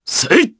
Roman-Vox_Attack2_jp.wav